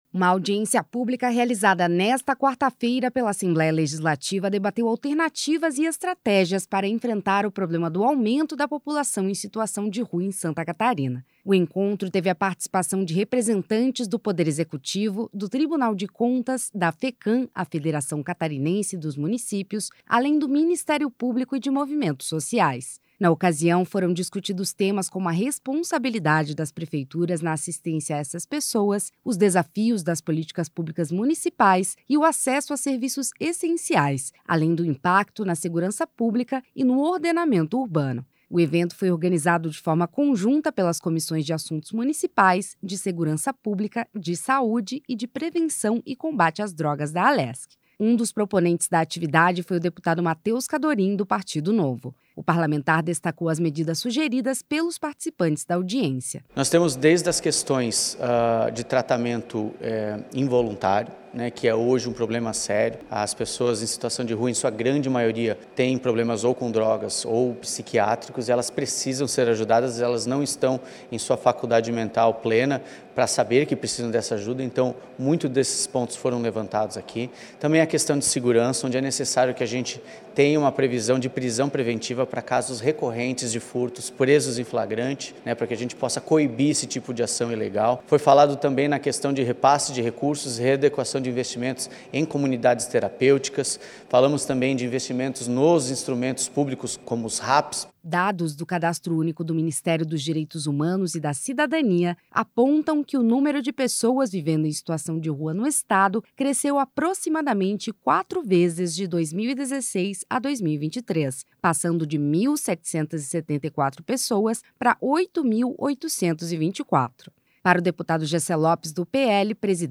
Entrevistas com:
- deputado Matheus Cadorin (Novo), proponente da audiência pública;
- deputado Jessé Lopes (PL), proponente da audiência pública;
- deputado Jair Miotto (União), proponente da audiência pública.